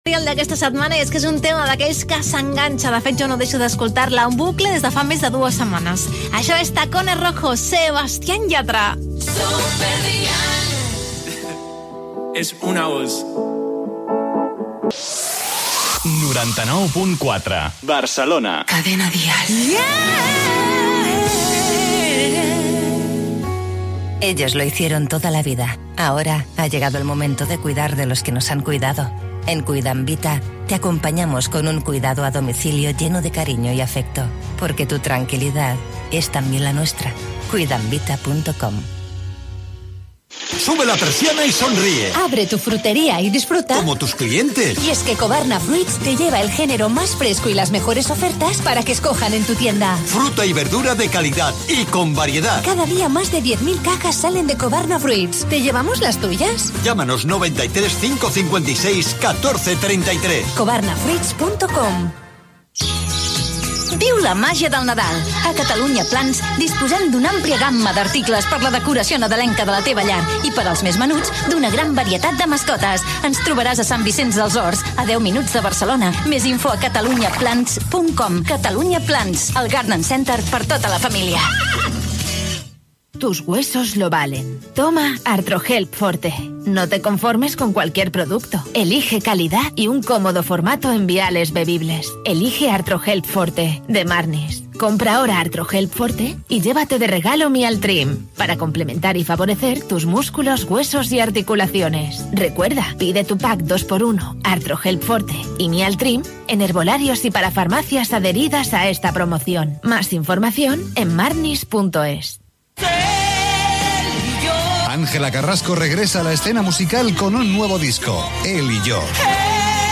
Tema musical, indicatiu, publicitat, promoció dels locutors de l'emissora, indicatiu.